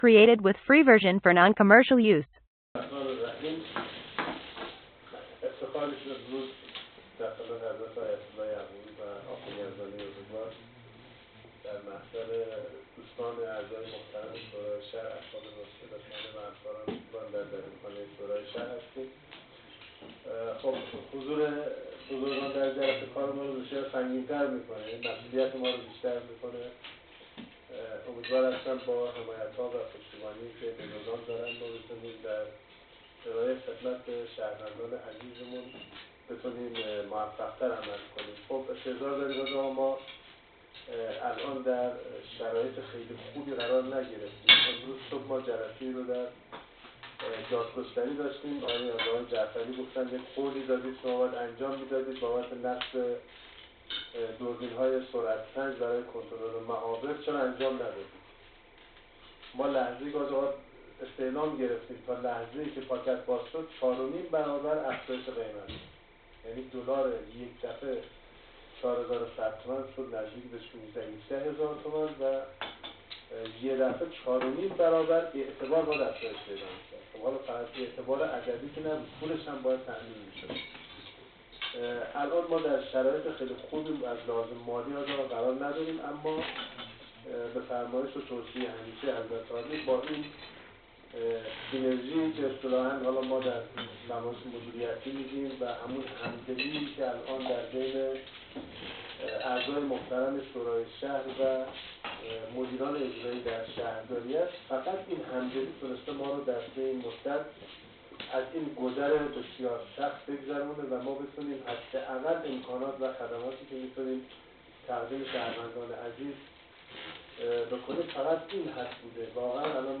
شصت و ششمین جلسه شورای اسلامی شهر بجنورد برگزار شد.
فایل صوتی جلسه: